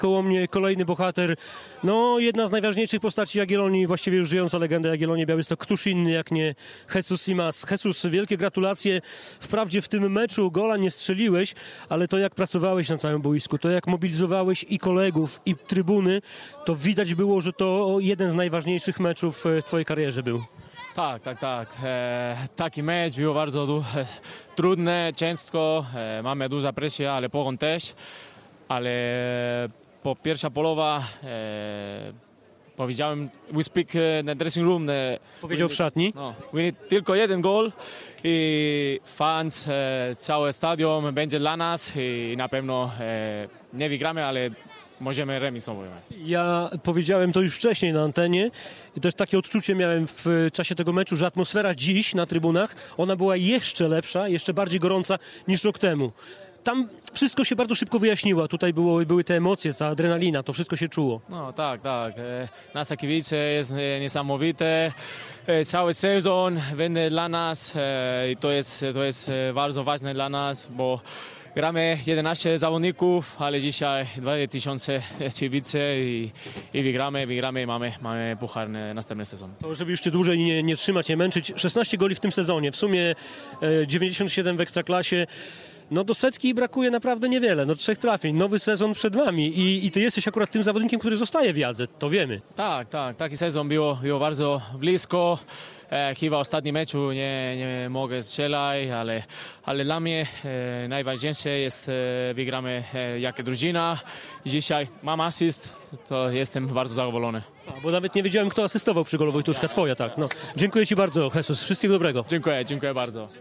Pomeczowy komentarz Jesusa Imaza